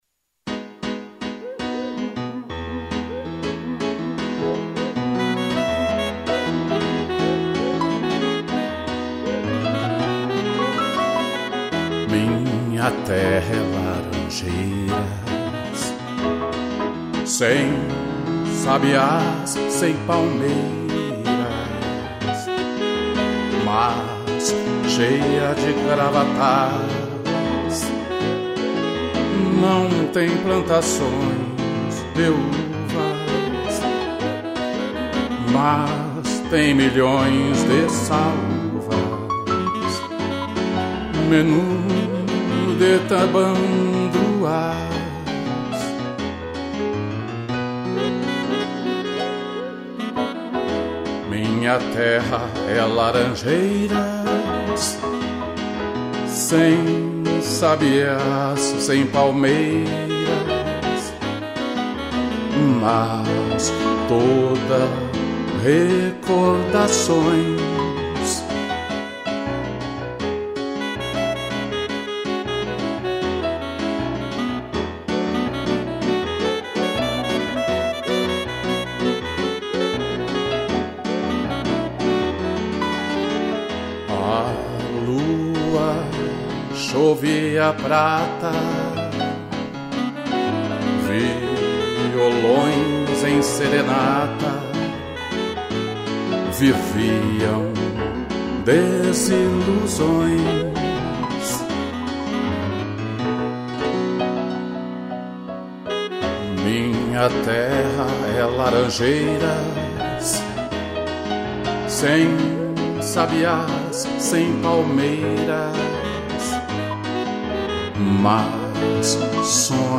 2 pianos, sax e cuíca